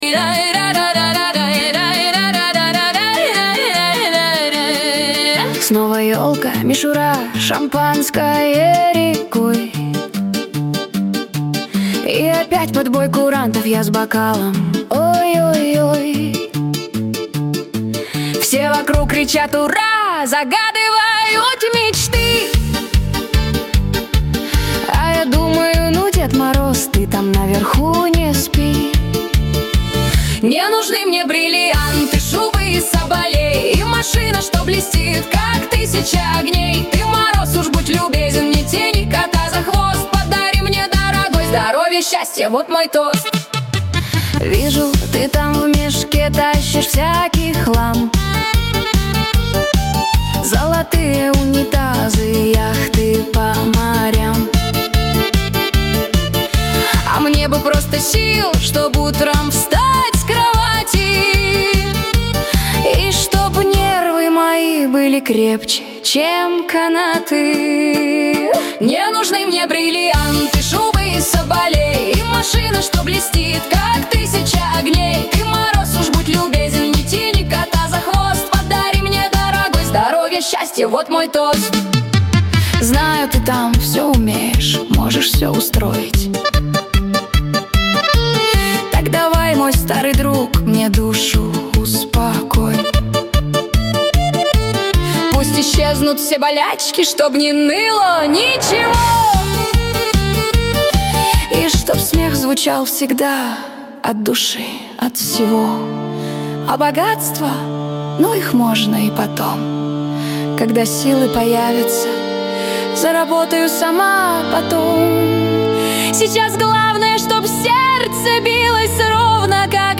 Категория: Хип-Хоп